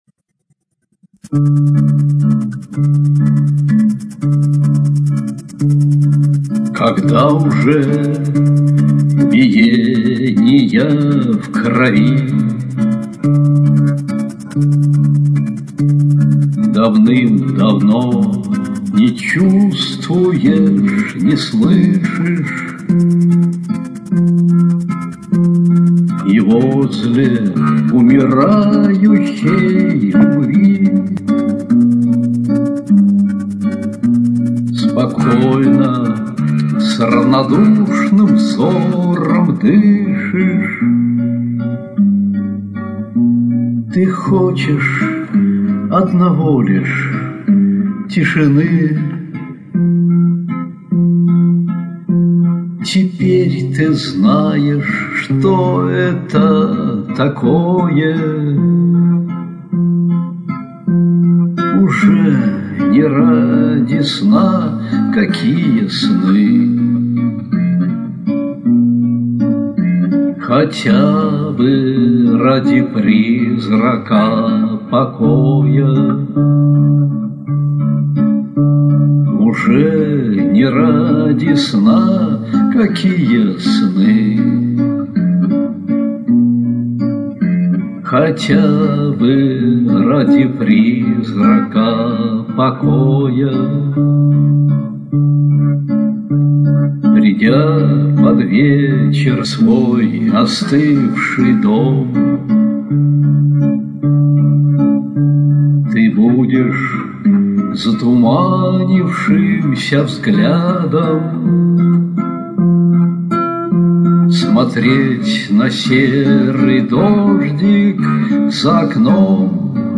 mp3,2194k] Романс